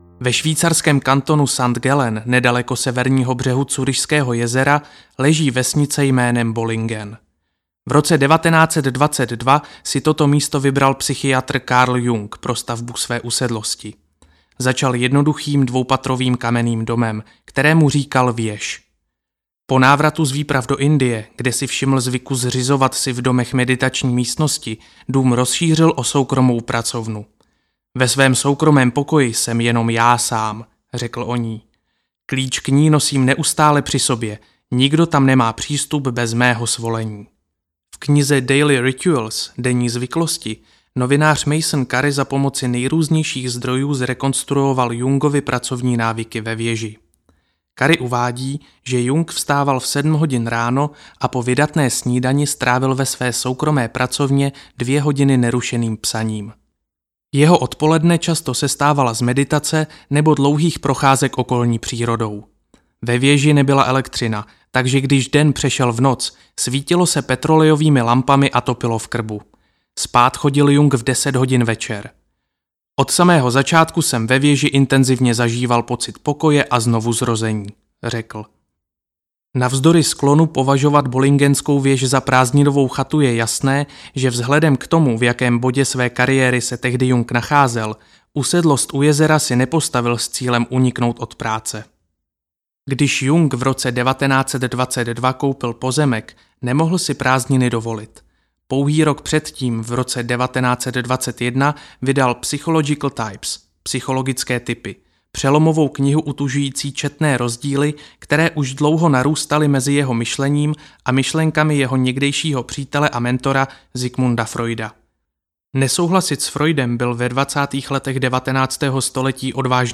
Hluboká práce audiokniha
Ukázka z knihy